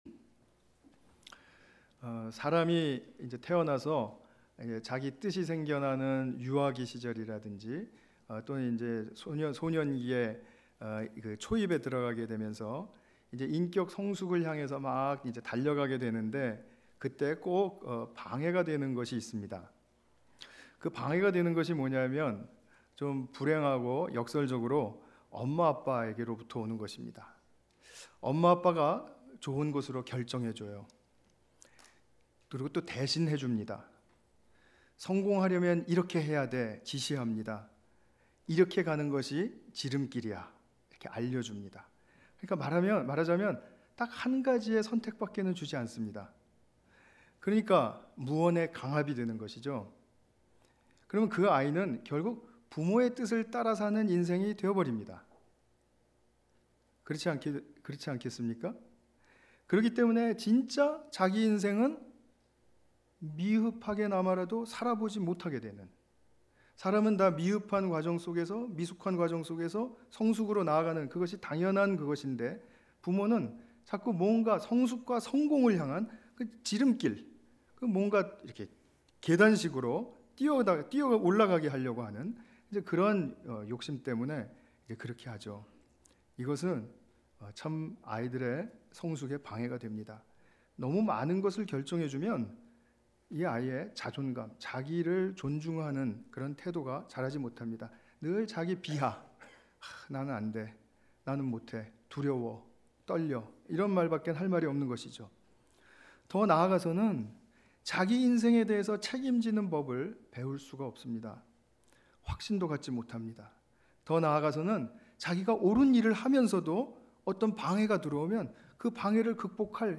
갈라디아서 1장 11 ~ 24절 관련 Tagged with 주일예배